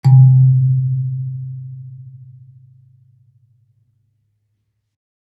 kalimba_bass-C2-ff.wav